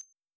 High Tone.wav